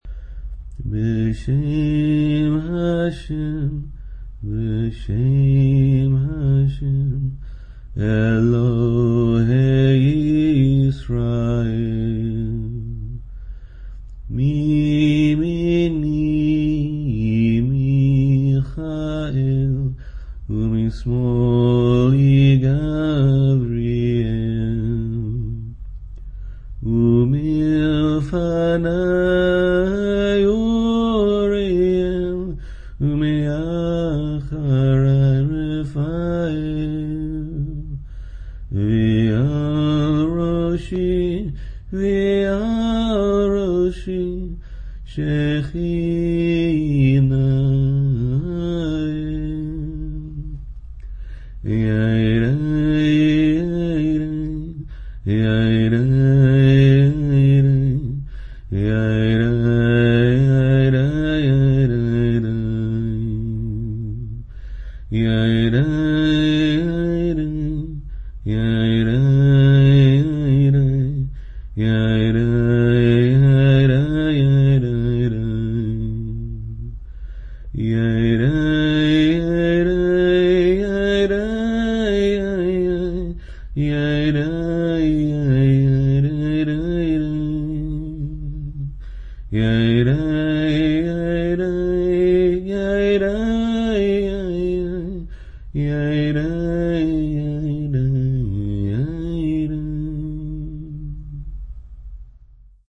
lullaby
The nigun is sung here